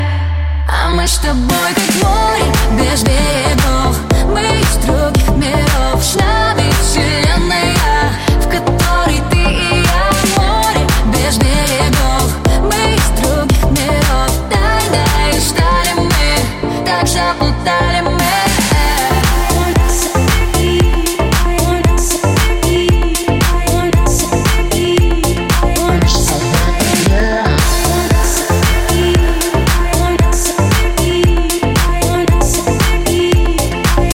• Качество: 128, Stereo
поп